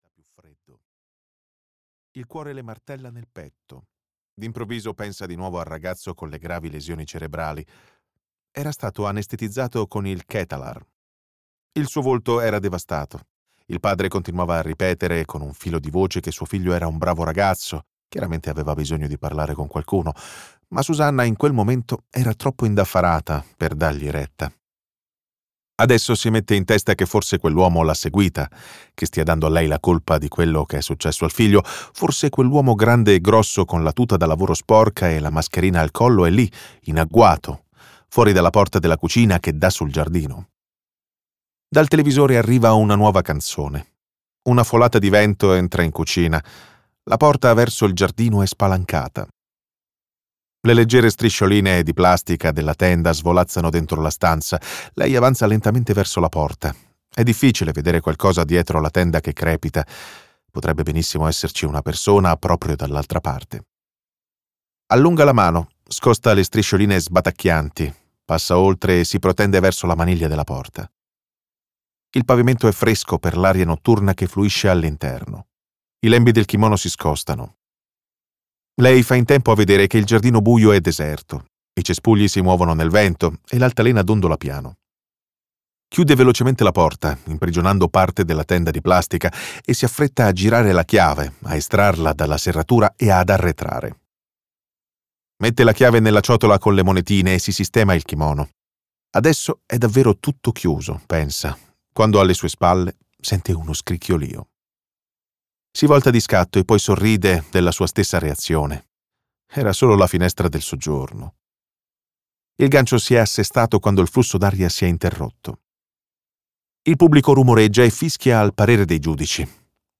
"Nella mente dell'ipnotista" di Lars Kepler - Audiolibro digitale - AUDIOLIBRI LIQUIDI - Il Libraio